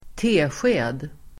tsk förkortning (i matrecept), tsp [in recipes]Uttal: [²t'e:sje:d] Definition: tesked (teaspoon)